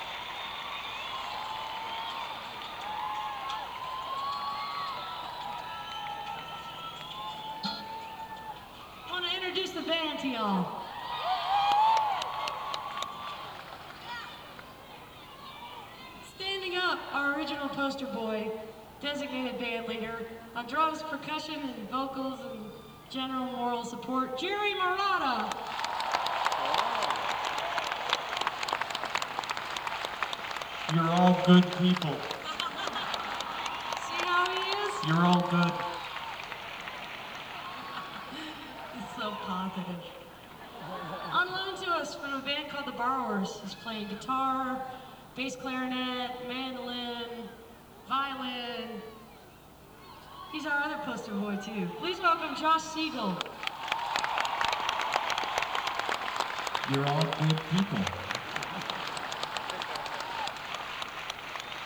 lifeblood: bootlegs: 1997-07-06: the greek theatre - berkeley, california (alternate recording)
03. band introductions (1:01)